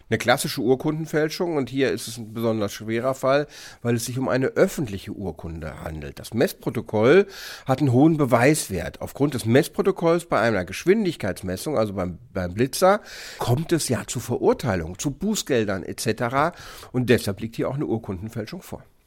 Auto, DAV, O-Töne / Radiobeiträge, Ratgeber, Recht, , , , , , , ,